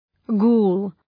Shkrimi fonetik {gu:l}